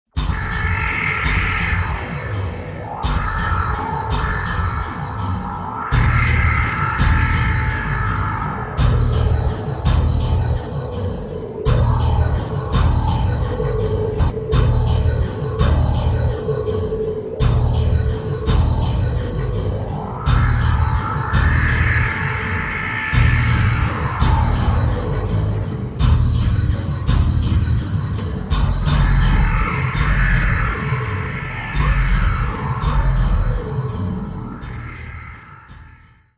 • Piste 13  -  [0'36]  -  Monstrueuse !